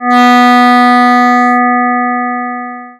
The difference here is that one totally omits the fundamental frequency of 245.88 Hertz (which is pretty close to the B below middle C assuming A440 tuning); odd harmonics are represented though both include the second harmonic at 491.76 Hertz.